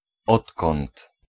Ääntäminen
IPA : /ʍɛns/ IPA : /wɛns/